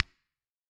Perc Funk 3.wav